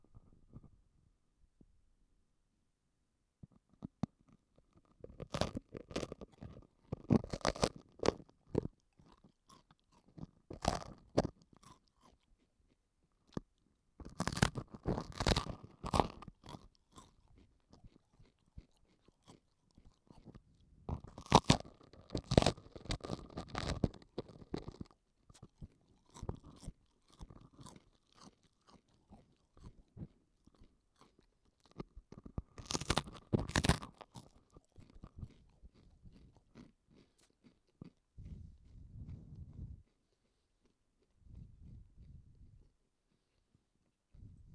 cat-pellets.wav